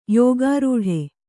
♪ yōgārūḍhe